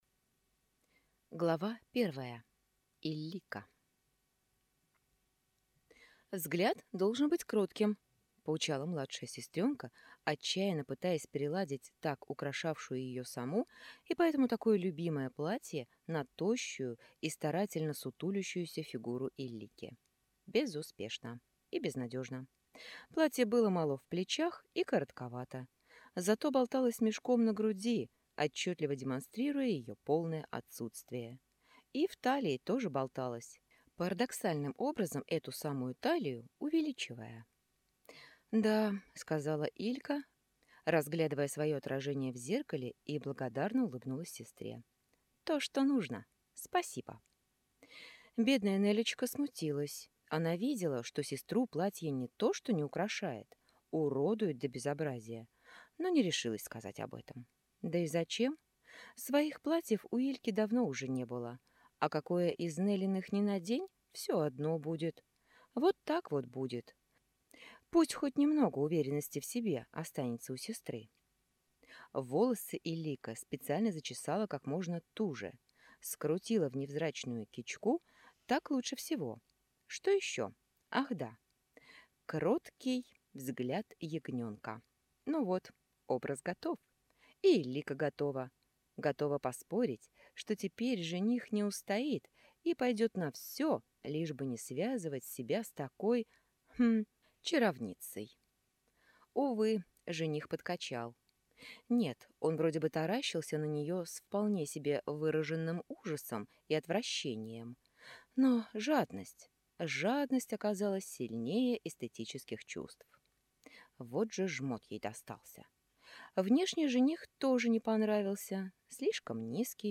Аудиокнига Иллика и Оккар | Библиотека аудиокниг
Прослушать и бесплатно скачать фрагмент аудиокниги